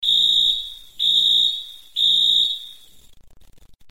короткие
свисток
Звук СМС из набора под номером 8